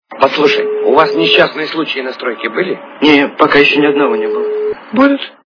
- Из фильмов и телепередач